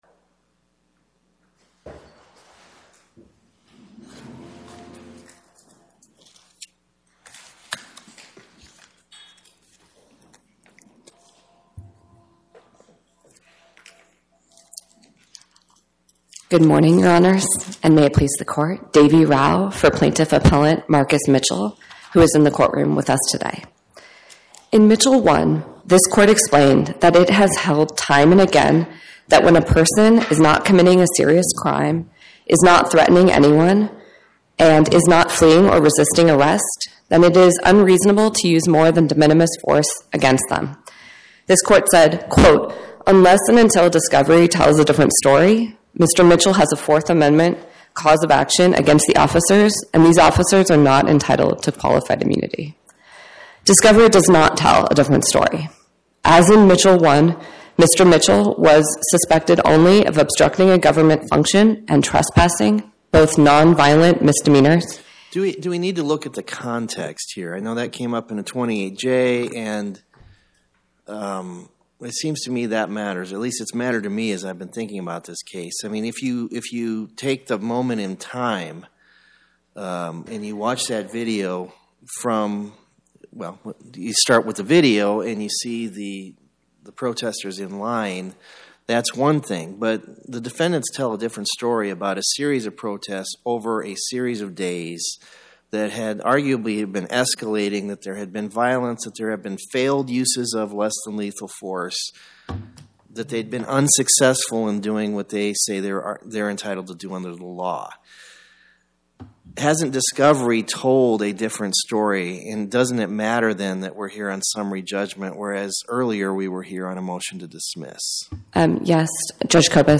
Oral argument argued before the Eighth Circuit U.S. Court of Appeals on or about 12/18/2025